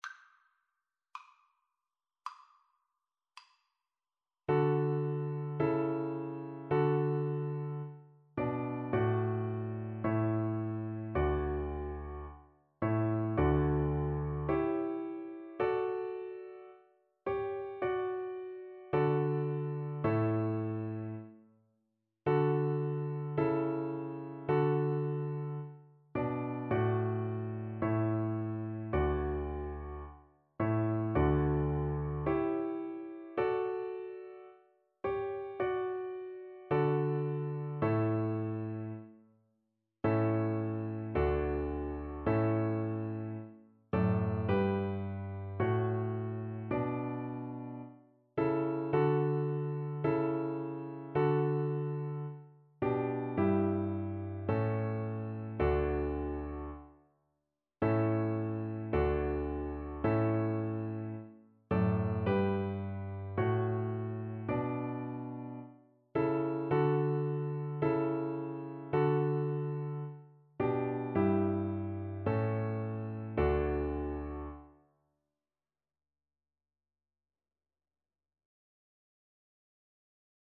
= 54 Slow
Classical (View more Classical Viola Music)